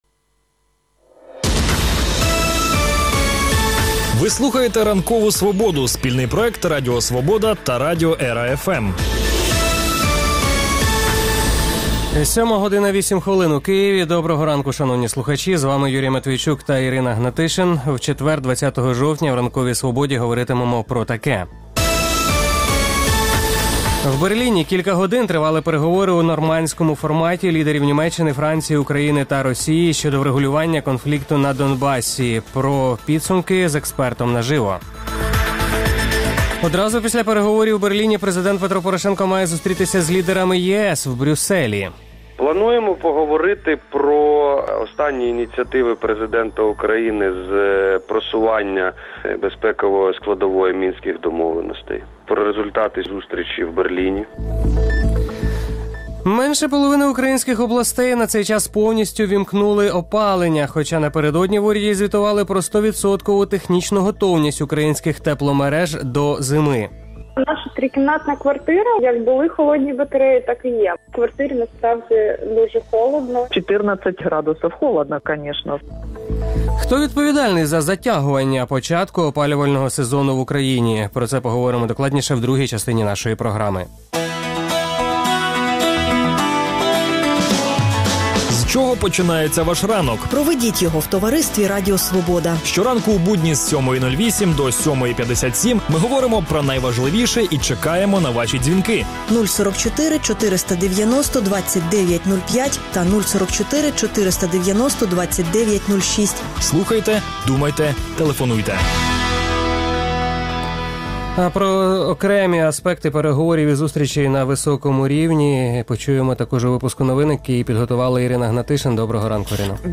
Також у програмі: У Берліні кілька годин тривали переговори у «нормандському форматі» щодо врегулювання конфлікту на Донбасі. Про підсумки – з експертом наживо | Одразу після переговорів у Берліні, президент Петро Порошенко має зустрітися з лідерами ЄС у Брюсселі.